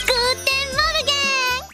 guten morgen Meme Sound Effect
guten morgen.mp3